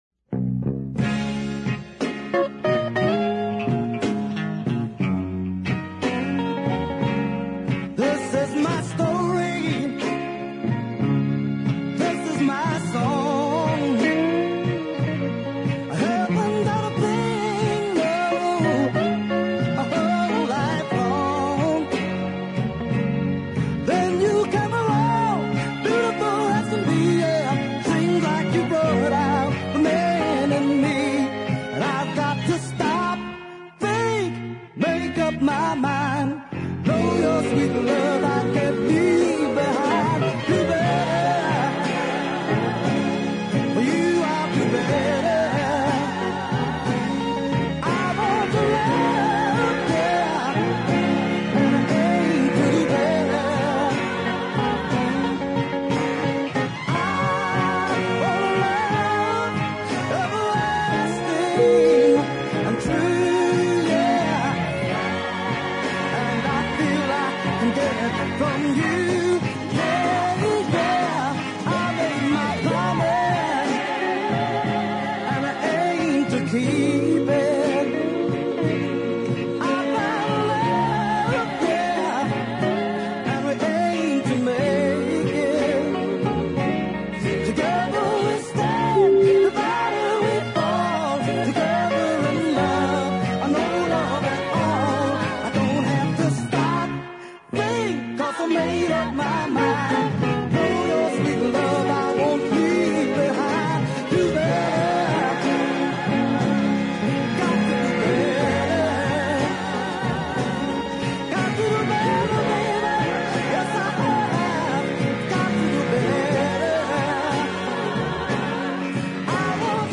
deep soul classic